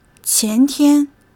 qian2 tian1.mp3